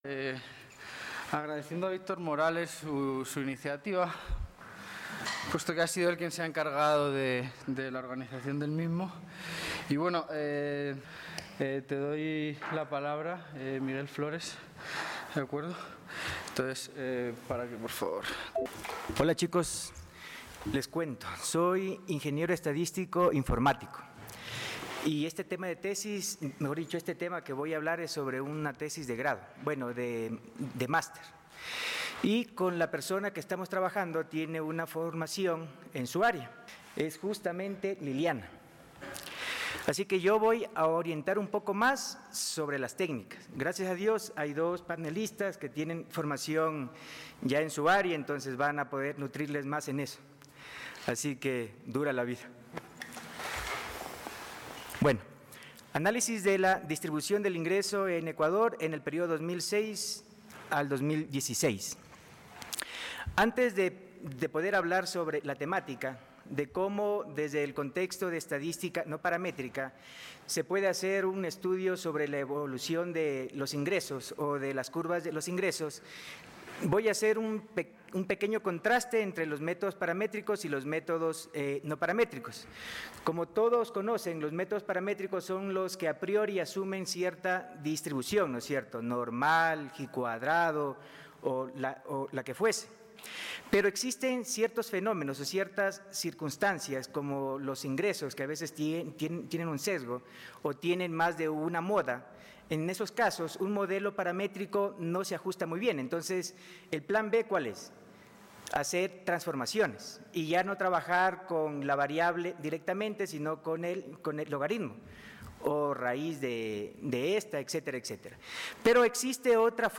Coloquio Estadistica